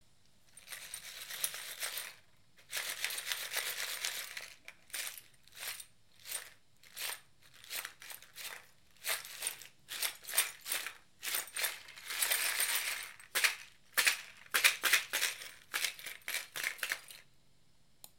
Kaeba Rattle / reed shaker filled with small stones. Rattles and jingles.
Kaeba-rattle.mp3